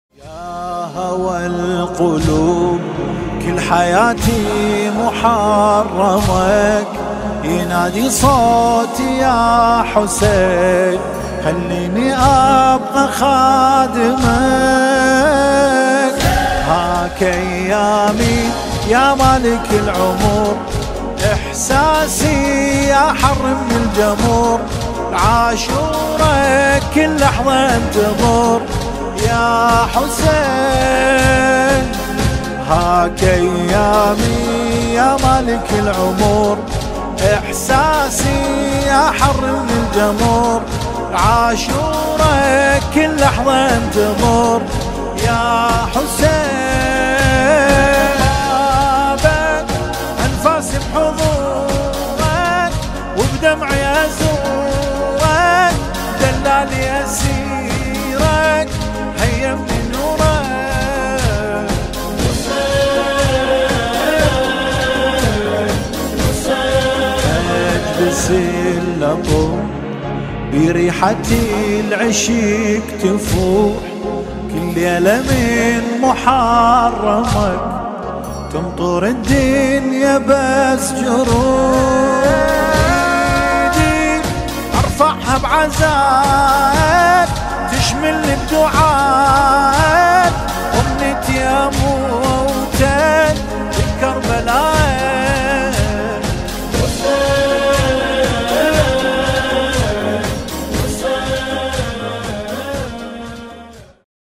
مداحی
چاووش محرم